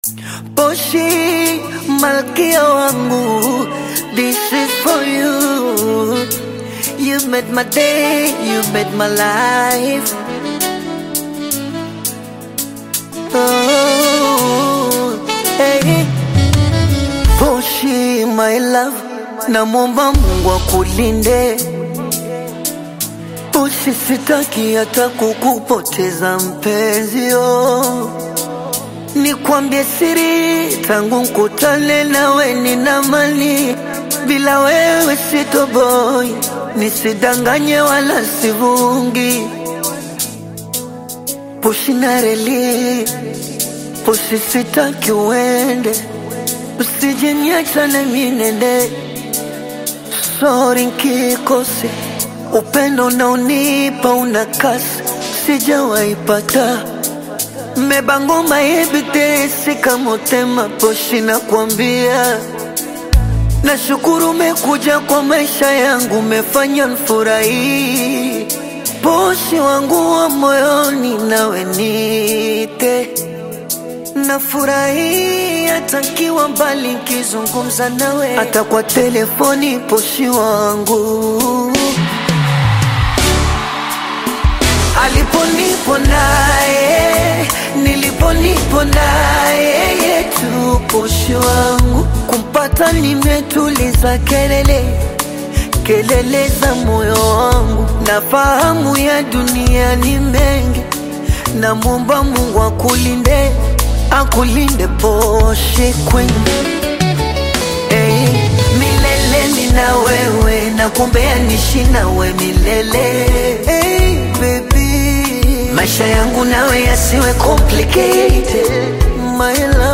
Bongo Flava
The track brings a smooth and romantic Bongo Flava vibe